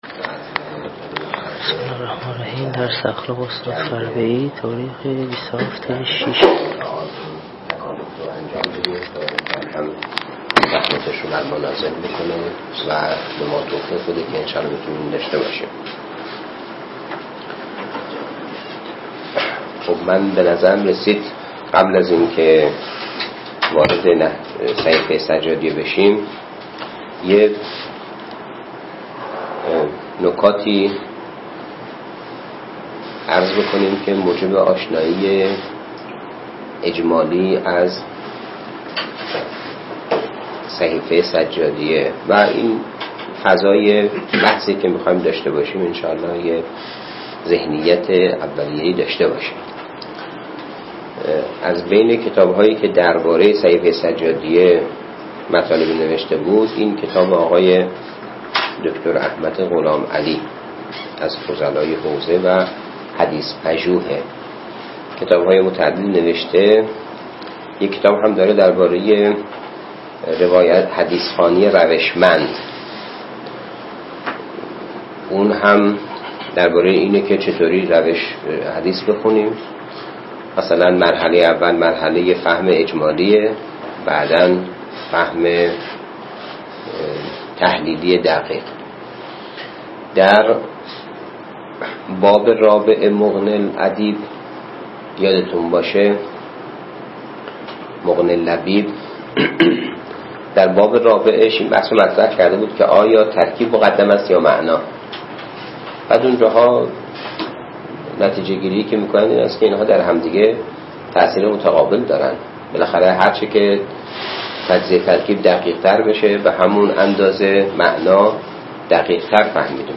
درس الاخلاق